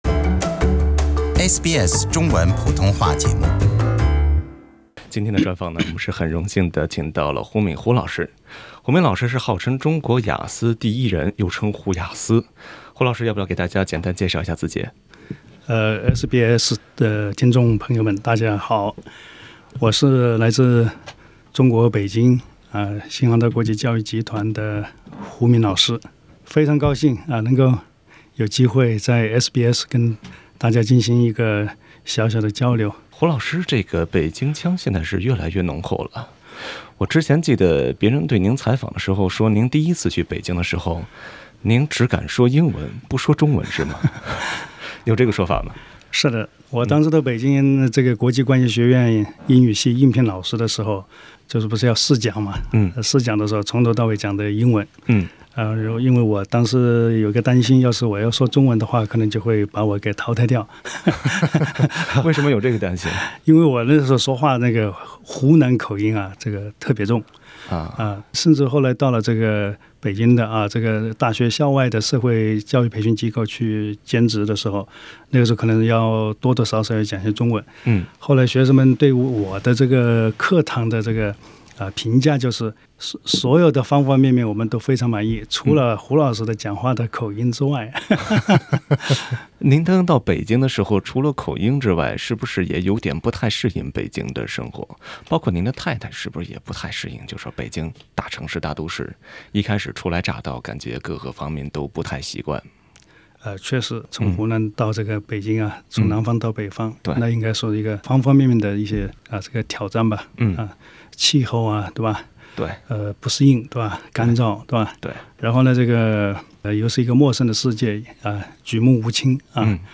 专访